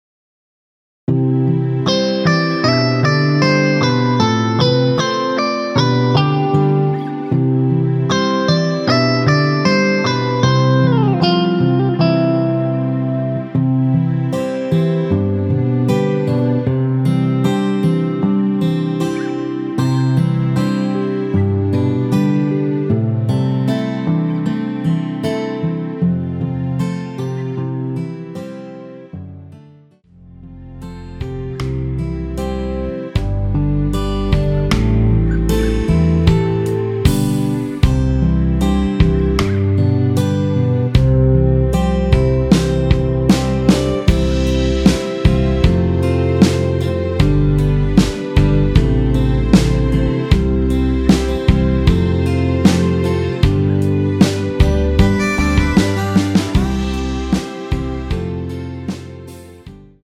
원키에서(-1)내린 (1절앞+후렴)으로 진행되는 MR입니다.
앞부분30초, 뒷부분30초씩 편집해서 올려 드리고 있습니다.